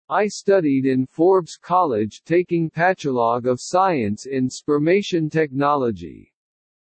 Play, download and share psstt original sound button!!!!